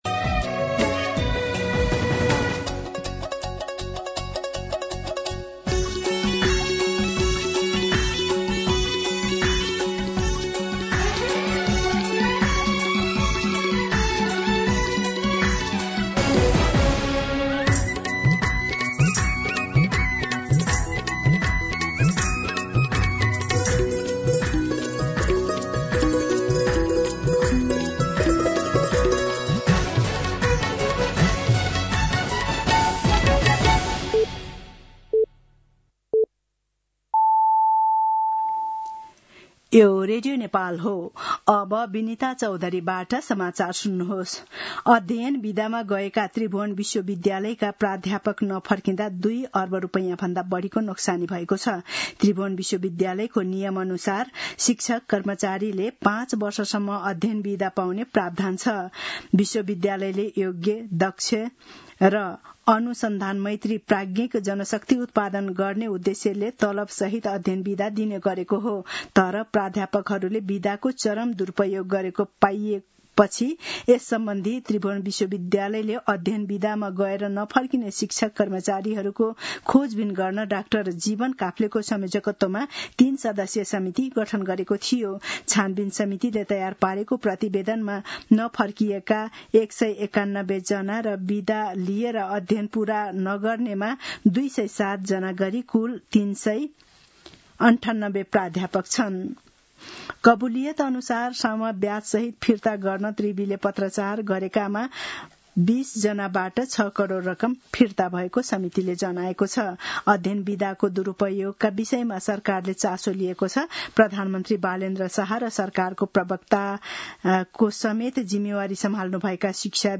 दिउँसो ४ बजेको नेपाली समाचार : ६ वैशाख , २०८३